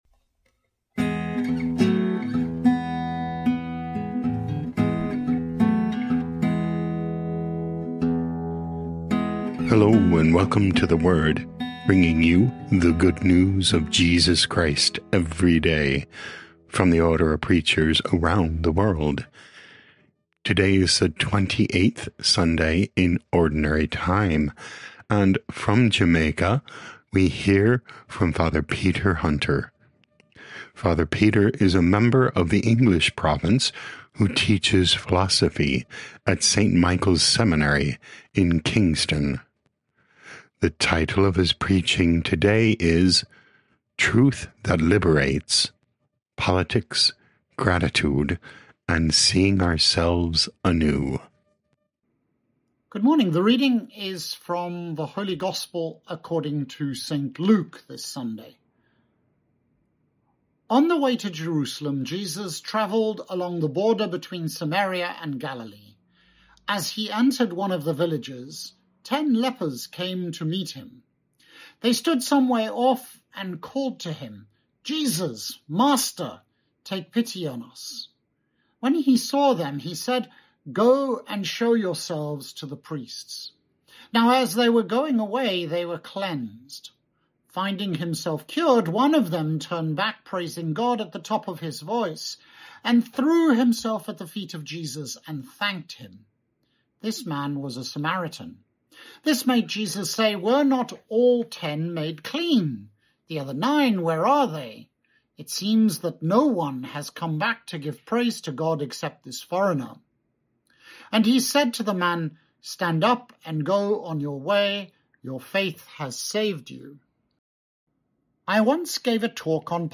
O.P. Preaching